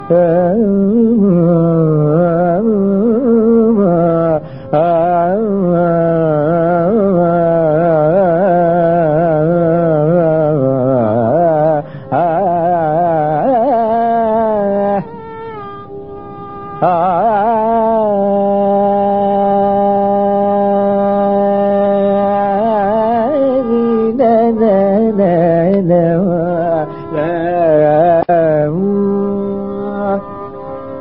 2-dwijavanti.mp3